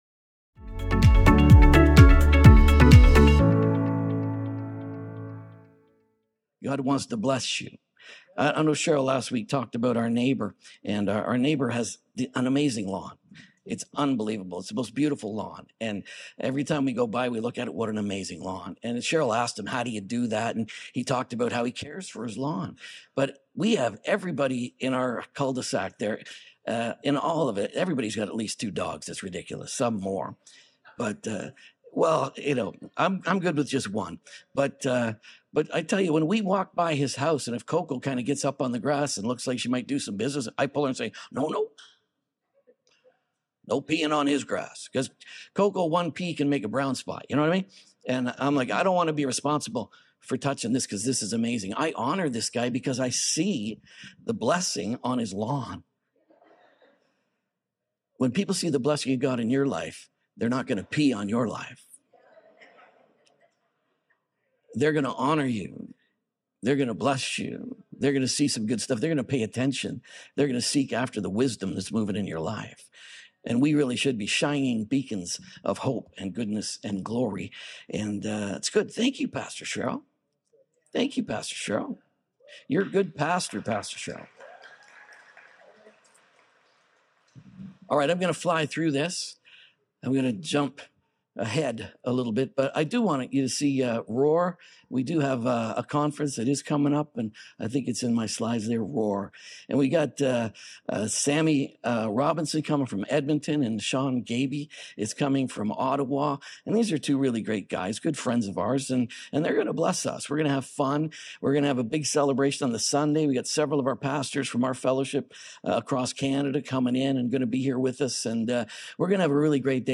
WHEN PUSH COMES TO PRAYER II | SERMON ONLY .mp3